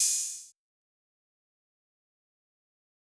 Closed Hats
SSBankrollHat.wav